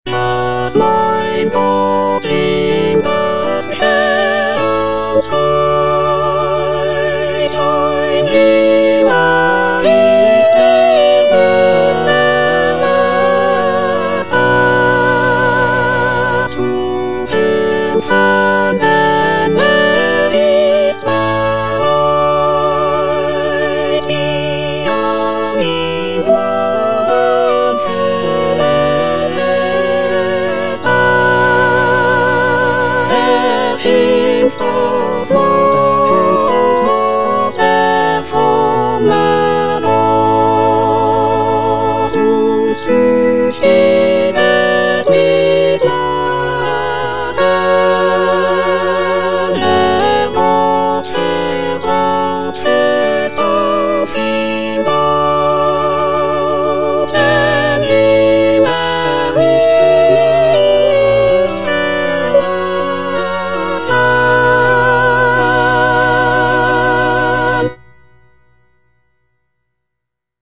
Soprano (Emphasised voice and other voices) Ads stop